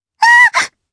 Lavril-Vox_Damage_jp_03.wav